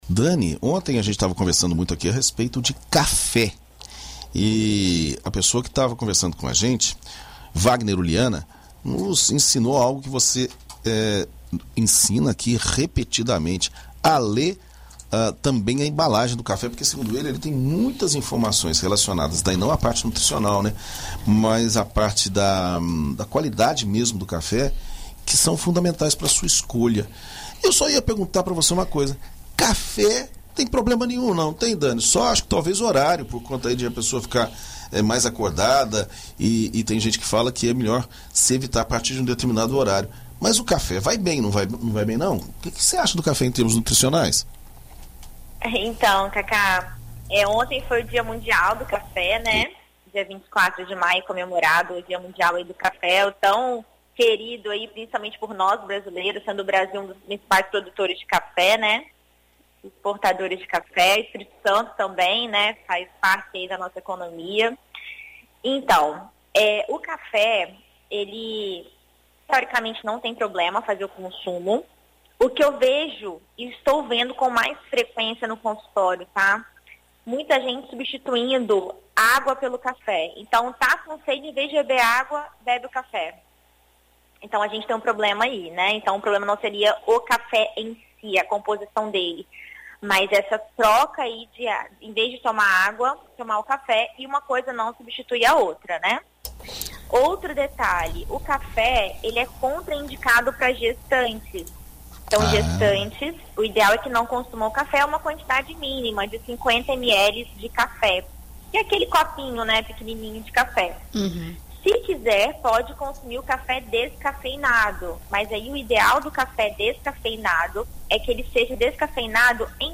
Na coluna Viver Bem desta quarta-feira (25), na BandNews FM Espírito Santo